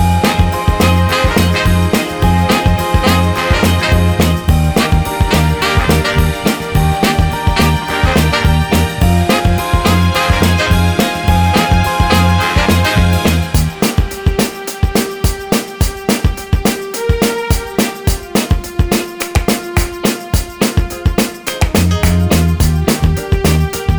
No Guitar Pop (2000s) 3:36 Buy £1.50